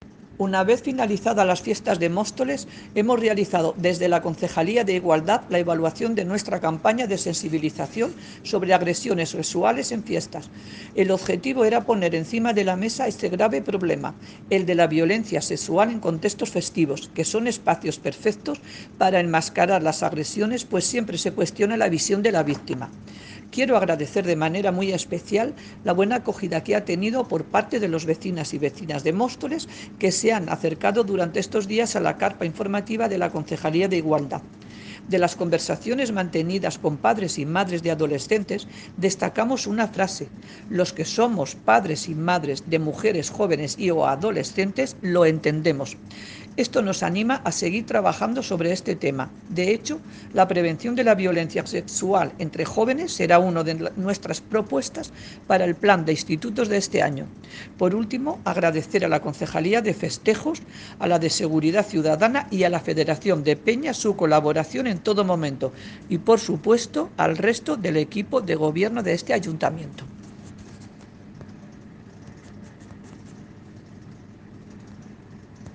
Audio - Ana Maria Rodrigo (Concejala de Igualdad, Sanidad y Mayores) Sobre CAMPAÑA-IGUALDAD